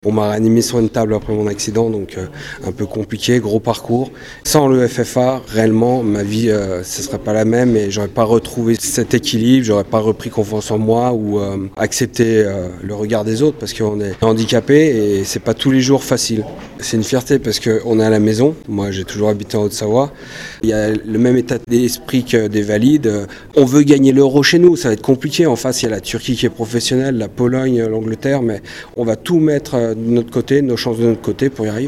un des joueurs de l’EFFA, l'équipe de France de football pour amputés